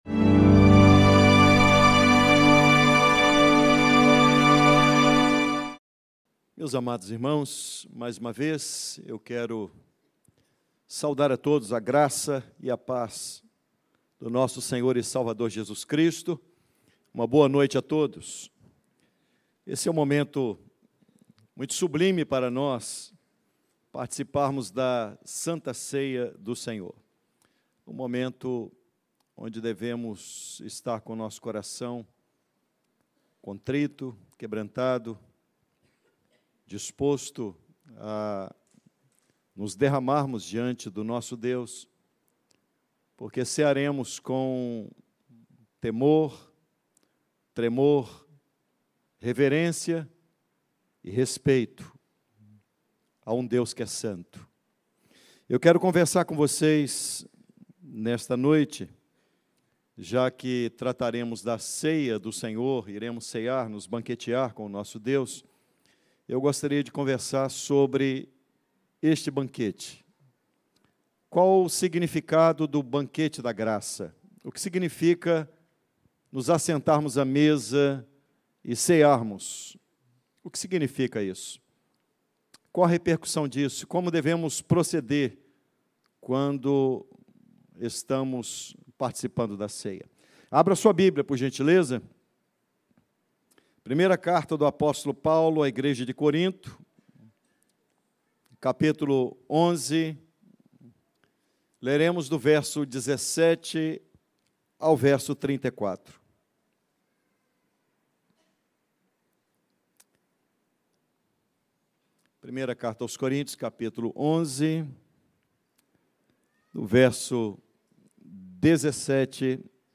O Banquete da Graça - Pregação Expositiva - Igreja Reformada em Vila Velha